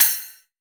Crashes & Cymbals
CRASH_Q.WAV